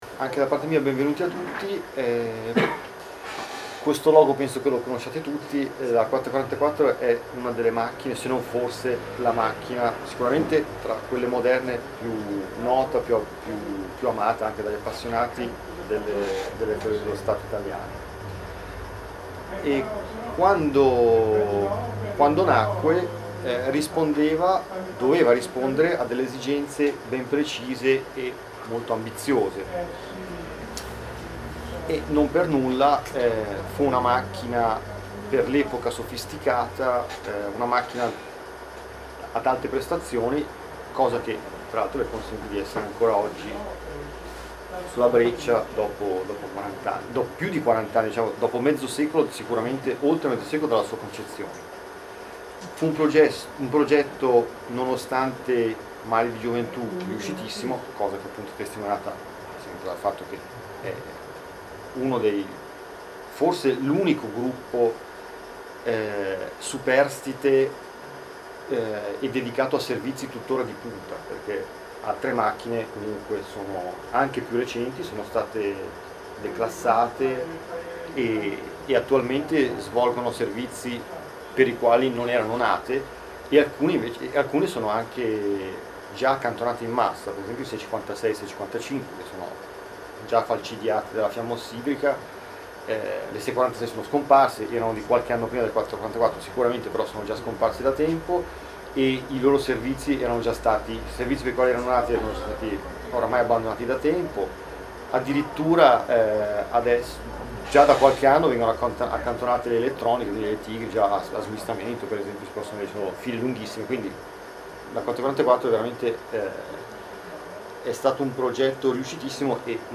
È disponibile la registrazione audio della conferenza del 13 febbraio 2015: